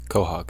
Ääntäminen
IPA : /ˈkoʊhɒɡ, ˈkoʊhɔːɡ/ IPA : /koʊ.hɒg/